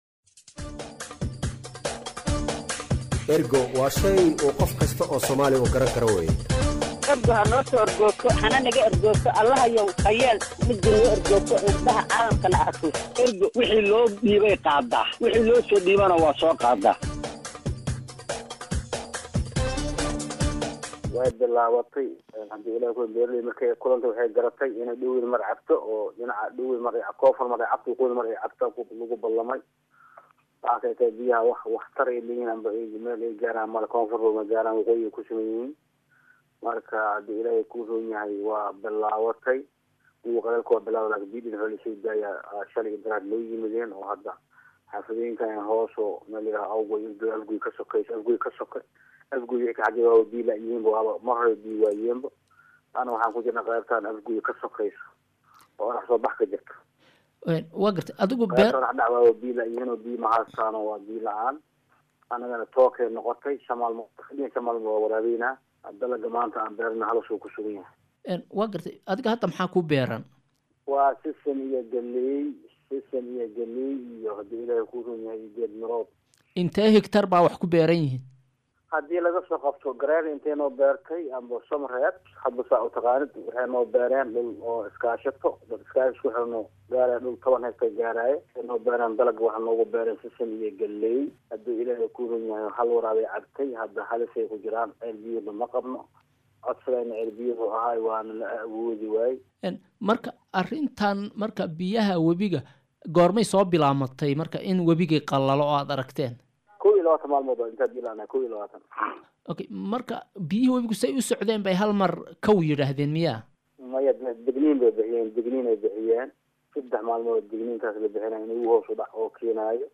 wareysi-beeraley.mp3